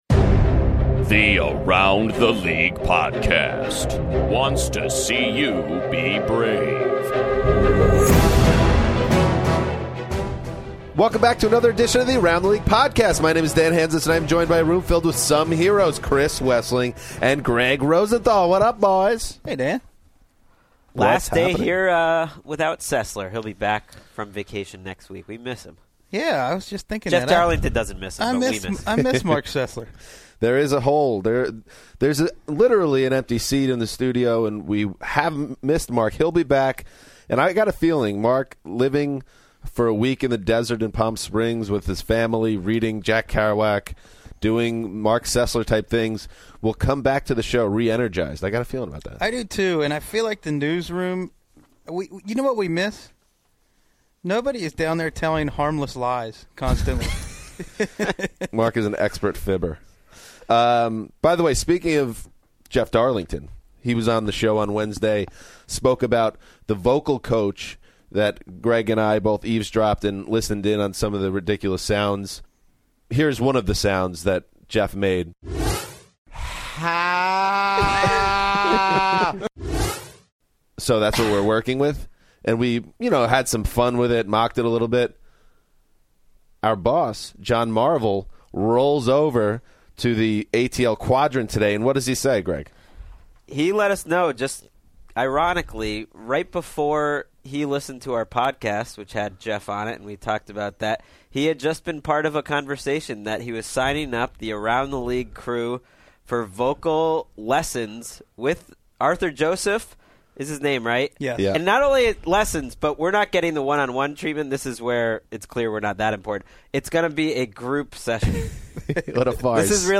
NFL ATL: Joe Haden in studio; Friday surprise!